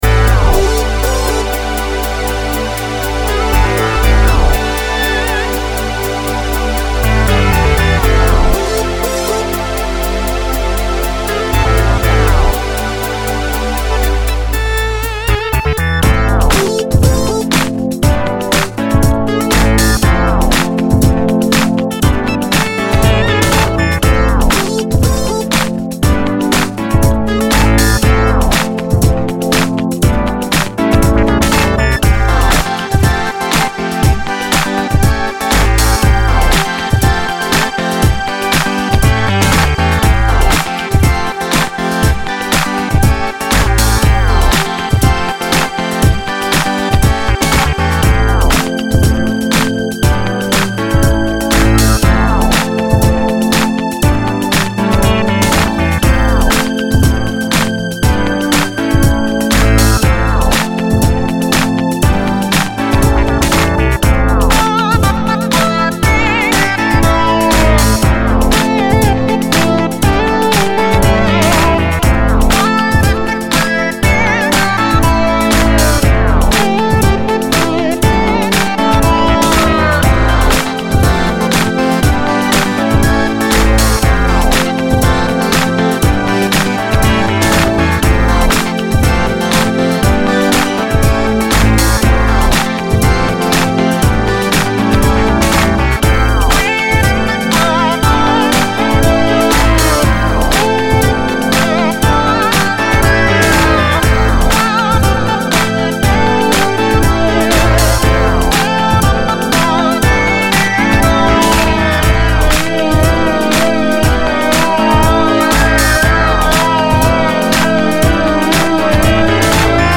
Sounds like 80' Roland JX3P
EVOL MP3 Demo 1 (funky) All internal FX